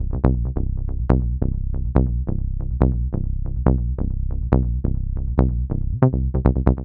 BL 140-BPM 3-C.wav